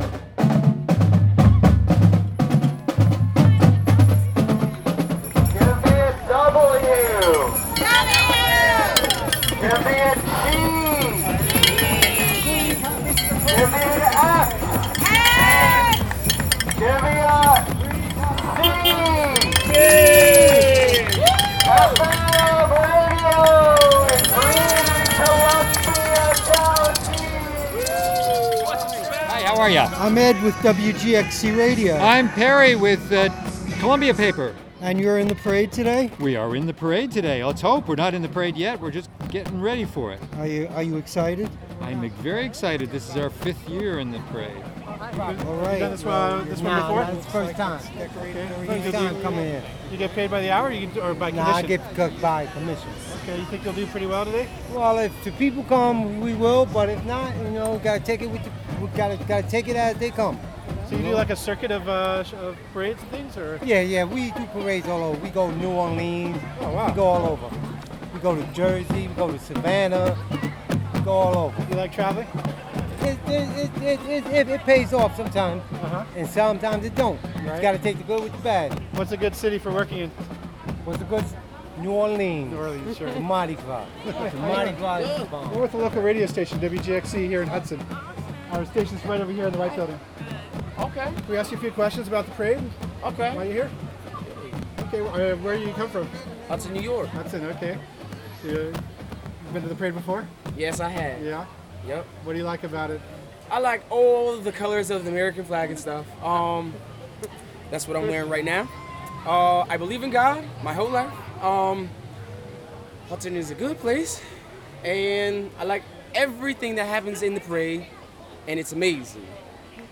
WGXC 90.7-FM at Hudson's Flag Day Parade 2013. (Audio)